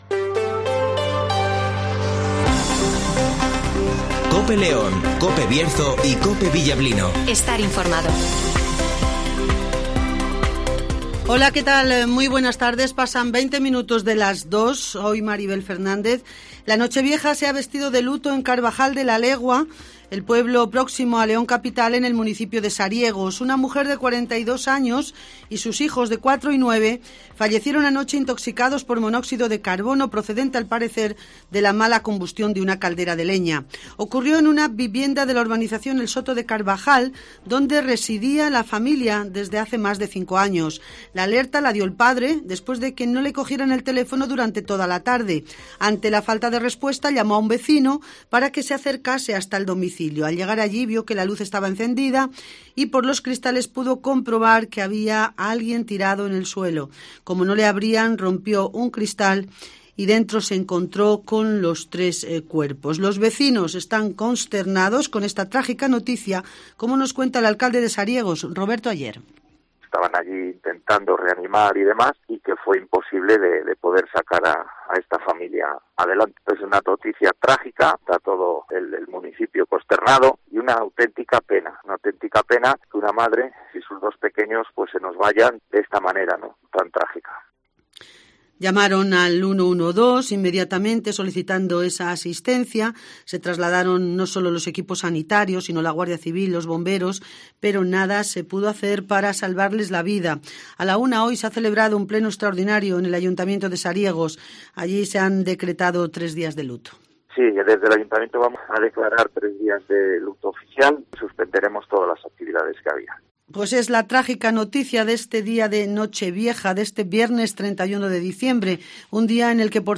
- Roberto Aller ( Alcalde de " Sariegos " )
- Alejandro Vázquez ( Consejero de Sanidad )
- Campanadas adelantadas desde Ponferrada